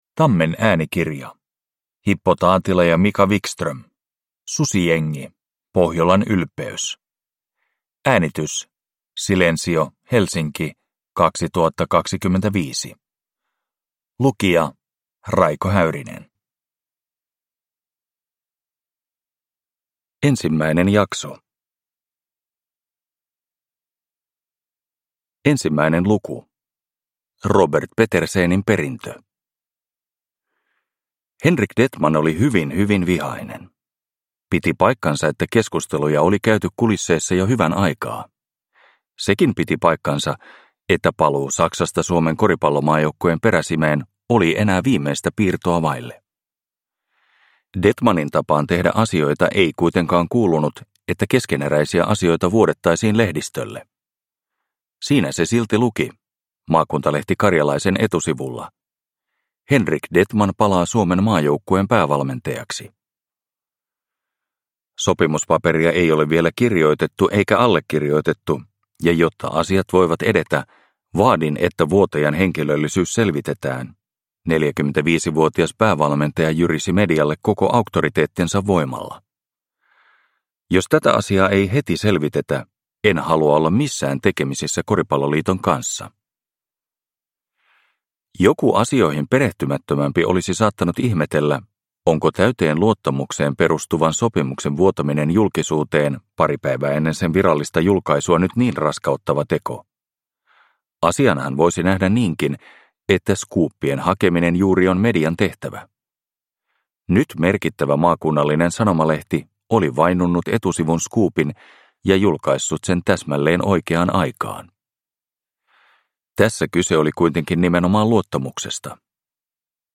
Susijengi - Pohjolan ylpeys – Ljudbok